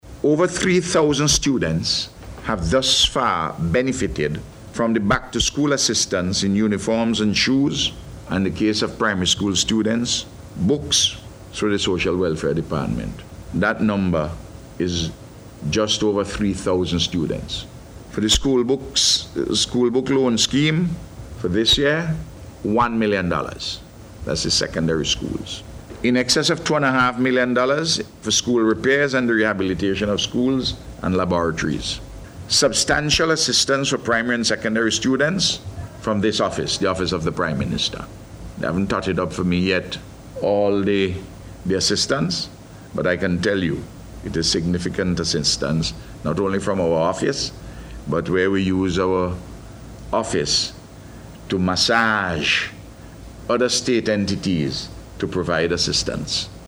Speaking at this morning’s News Conference, Prime Minister Dr. Ralph Gonsalves said assistance has been provided to students, through the Ministry of Social Development.